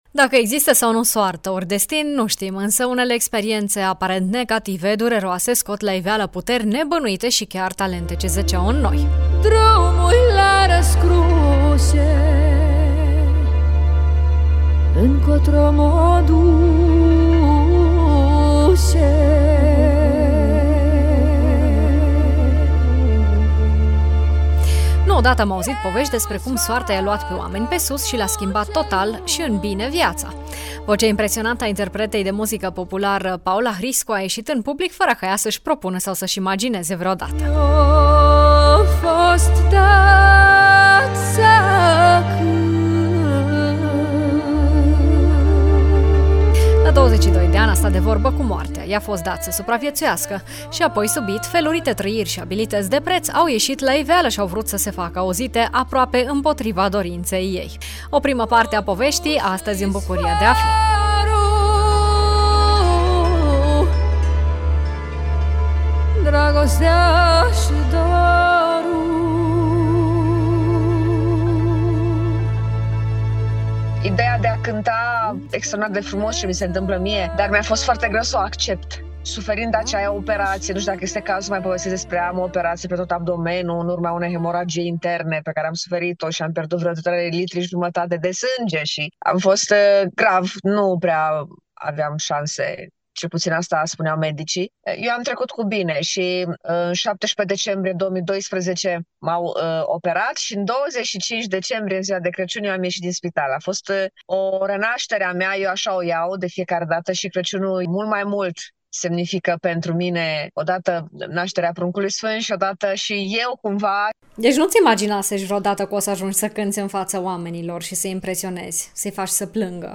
interpretă de muzică populară